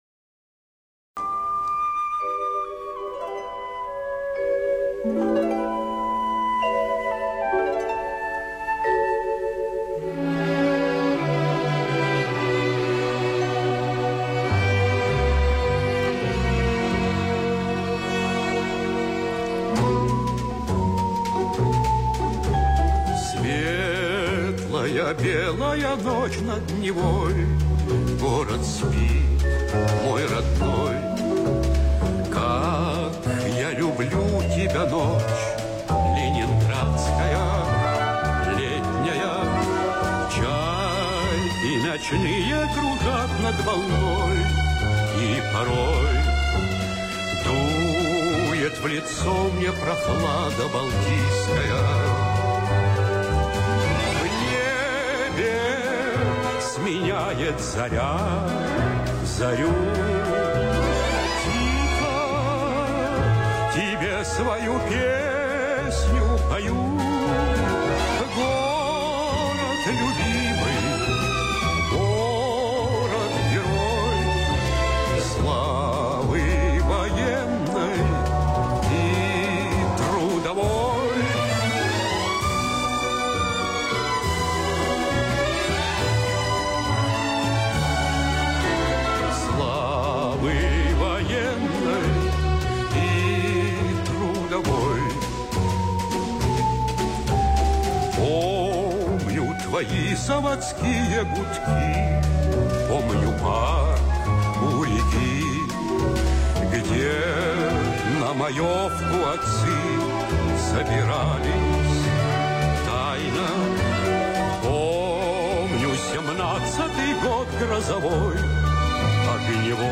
Из передачи по радио